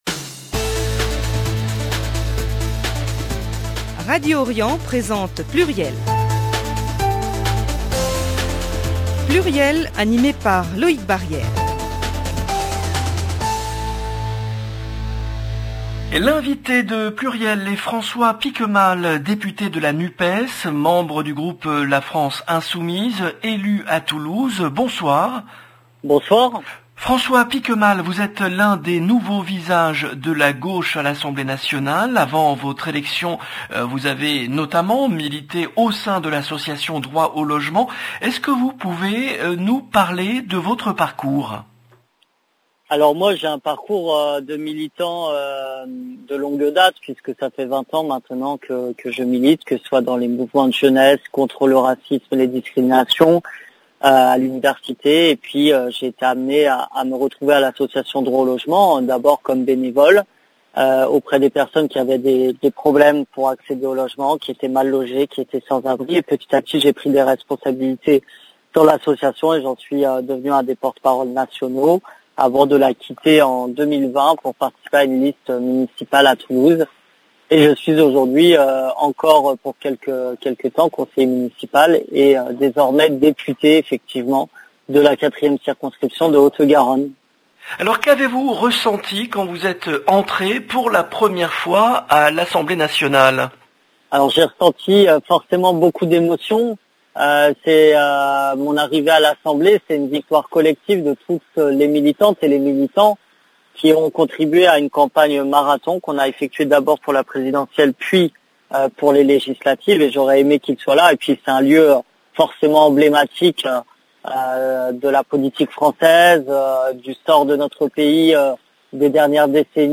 L’invité de PLURIELest François Piquemal , député de la NUPES, élu à Toulouse